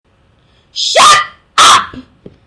Download Shut Up sound effect for free.